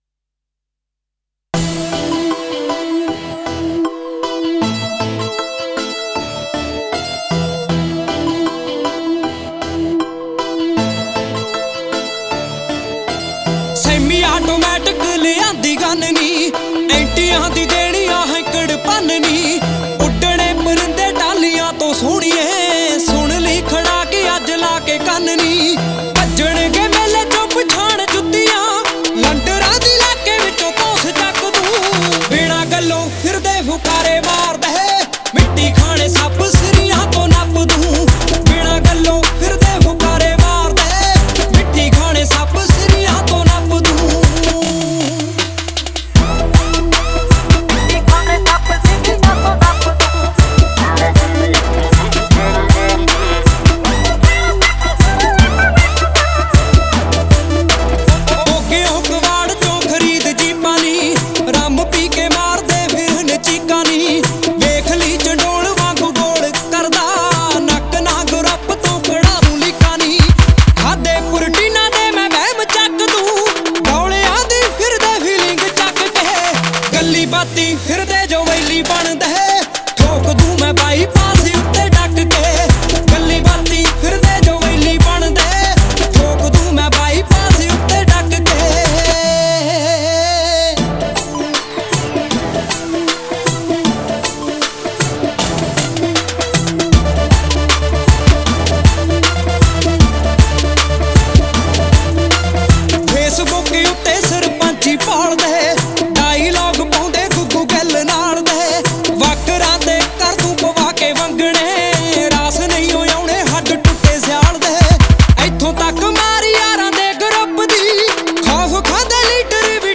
Genre Latest Punjabi Songs